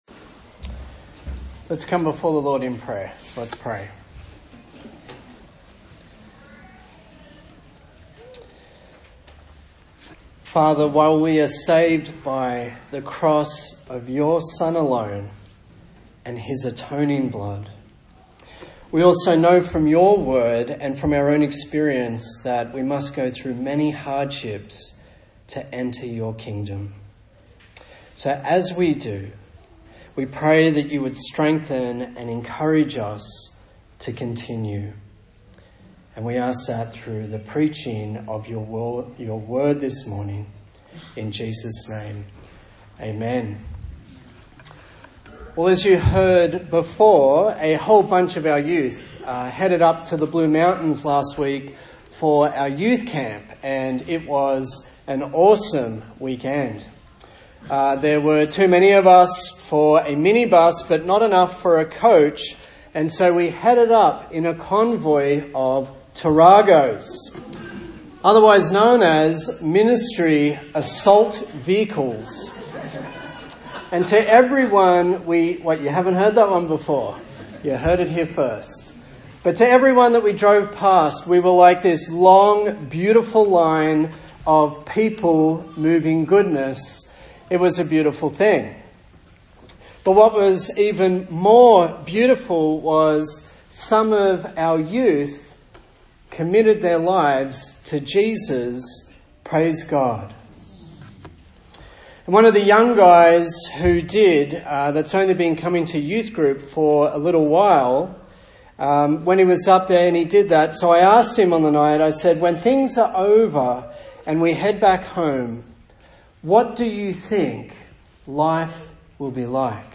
Service Type: Sunday Morning A sermon in the series on the book of 1 Peter